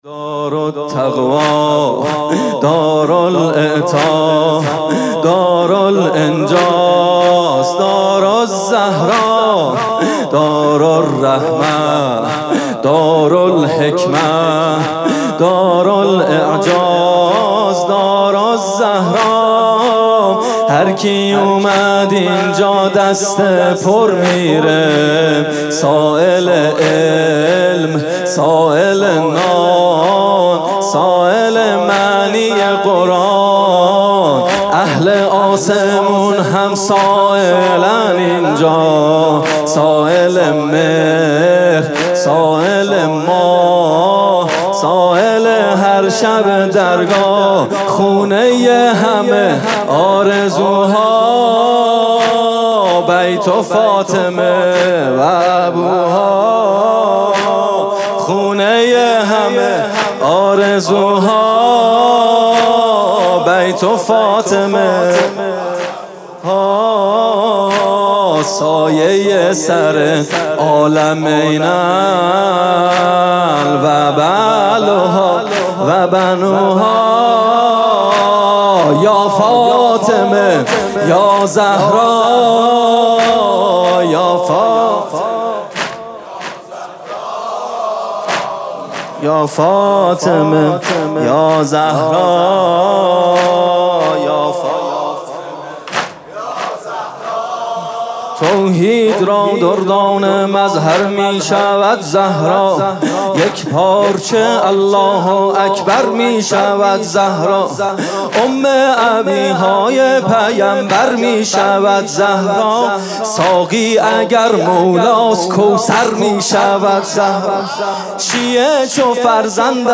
music-icon واحد: دارالرحمه، دارالحکمه، دارُالاعجاز، دارُالزهرا